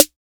Snare 001.wav